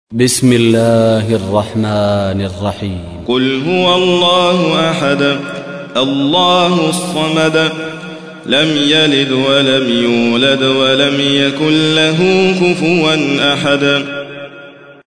تحميل : 112. سورة الإخلاص / القارئ حاتم فريد الواعر / القرآن الكريم / موقع يا حسين